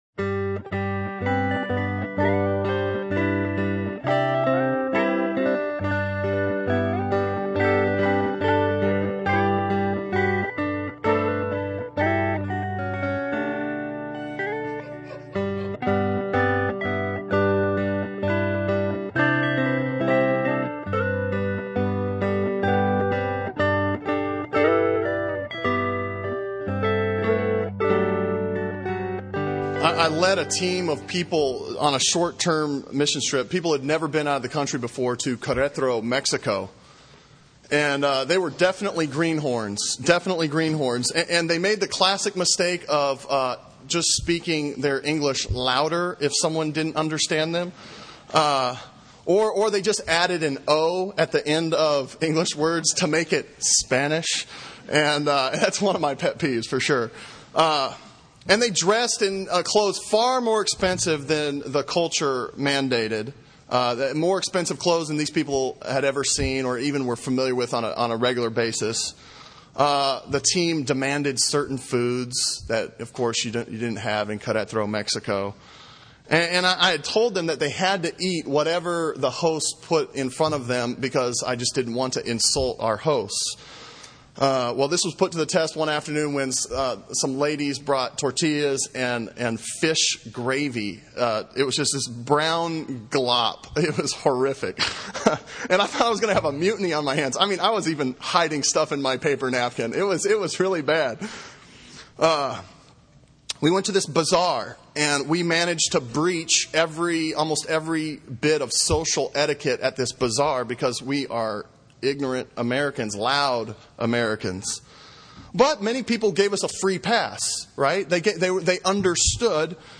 Sermon on Psalm 15 from April 13